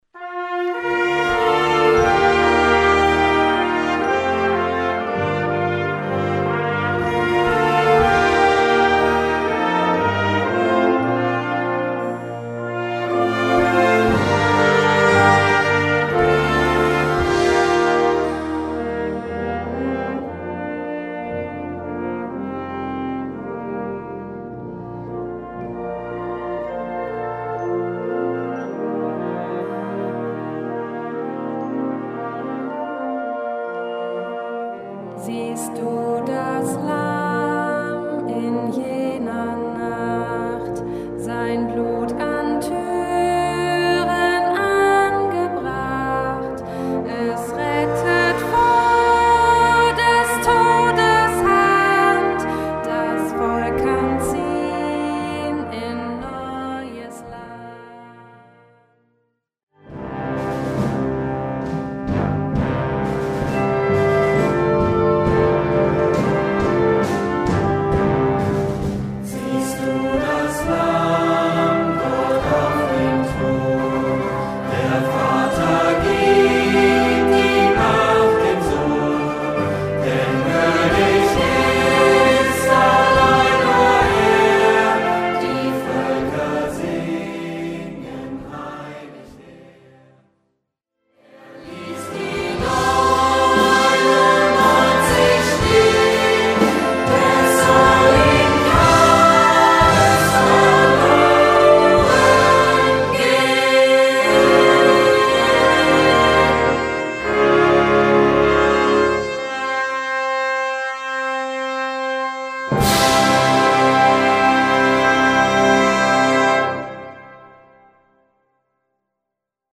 Gattung: Kirchenwerk mit Gesang (ad lib.)
Besetzung: Blasorchester